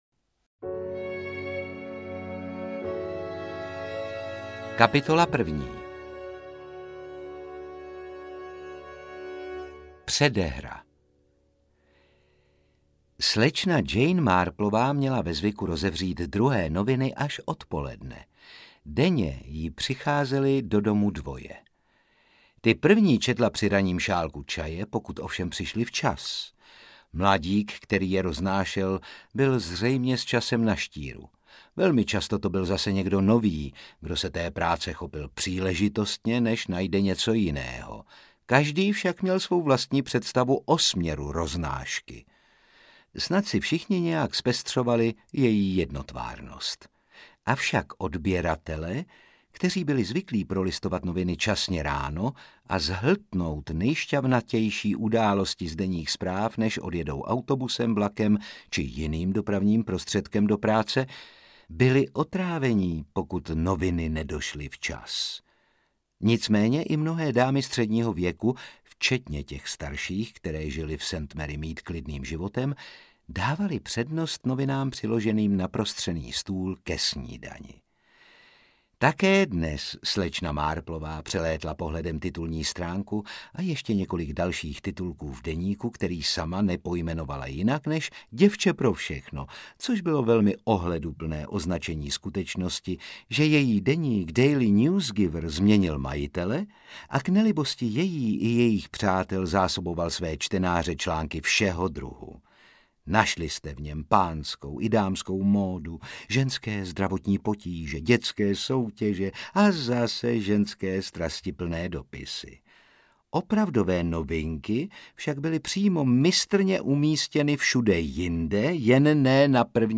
Audiobook
Read: Otakar Brousek Jr.